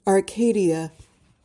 PRONUNCIATION:
(ahr-KAY-dee-uh)